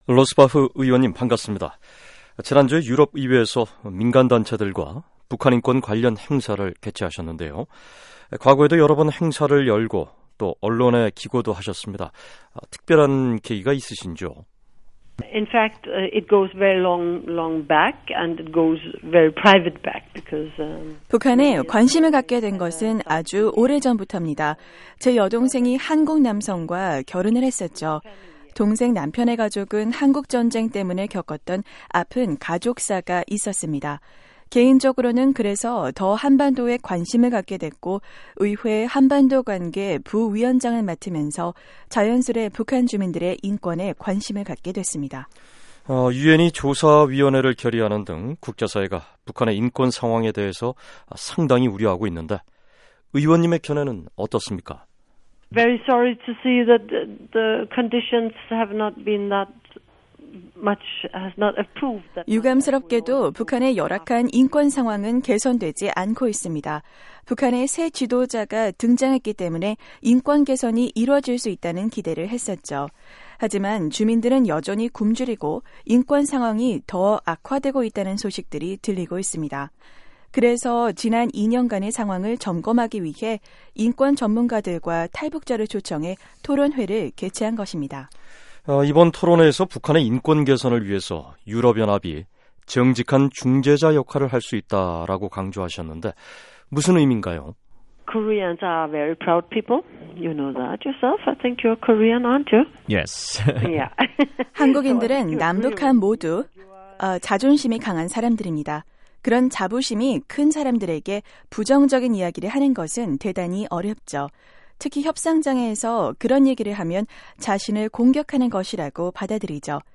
[인터뷰] 애나 로스바흐 유럽의회 한반도관계 부위원장 "북한 인권 개선, 중재 역할 희망"